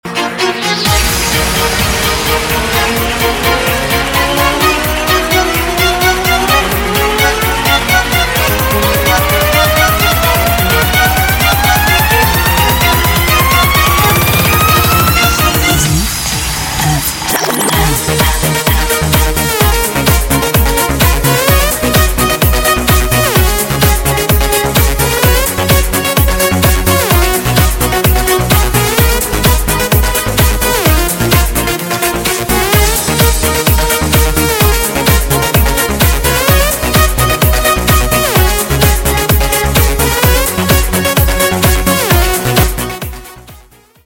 Из клубной музыки